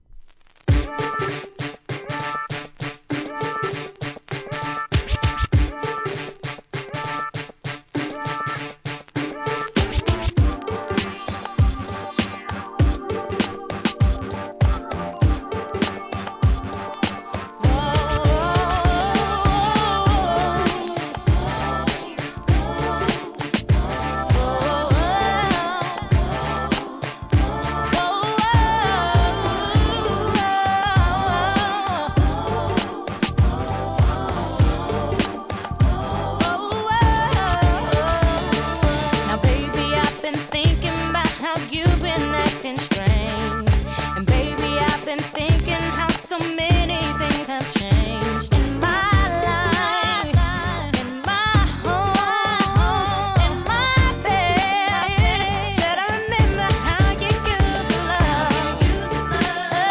HIP HOP/R&B
危なげない安定したヴォーカルスキルでソウルフルに歌い上げた好R&Bナンバー!!!